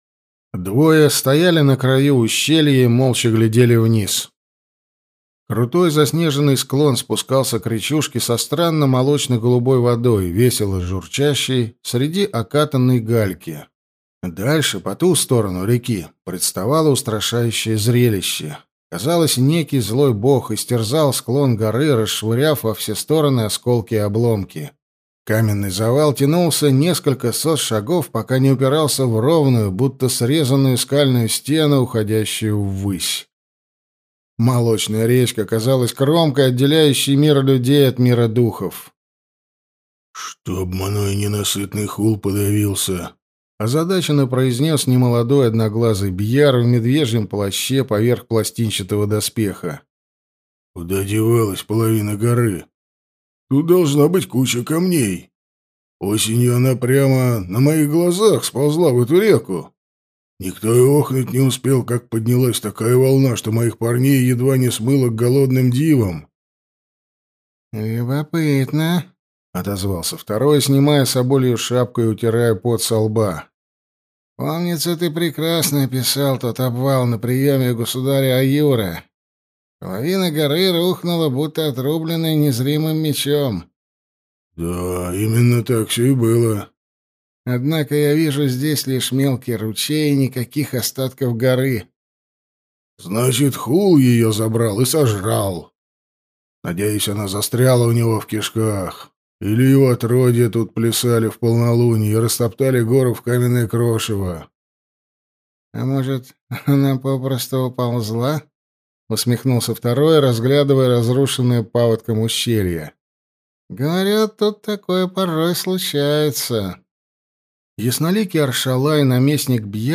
Аудиокнига Аратта. Книга 5. Зимняя жертва | Библиотека аудиокниг